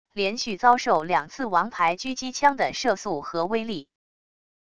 连续遭受两次王牌狙击枪的射速和威力wav音频